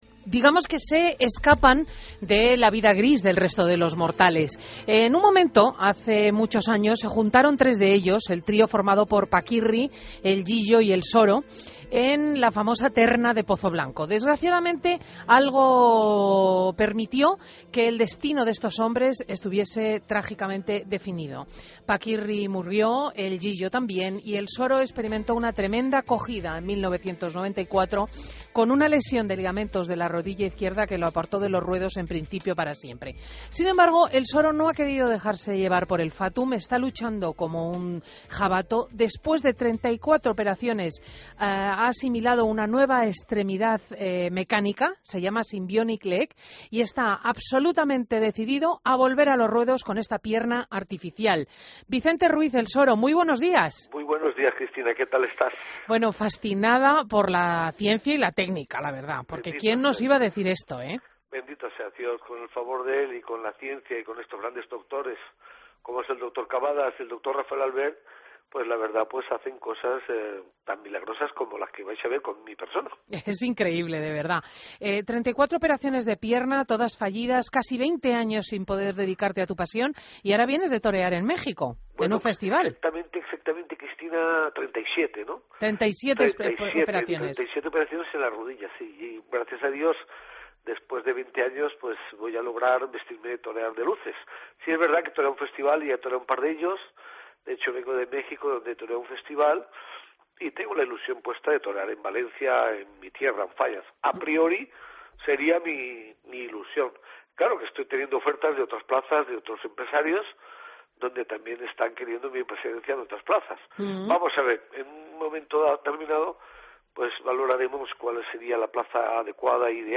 AUDIO: Entrevista a Vicente Ruiz "El Soro"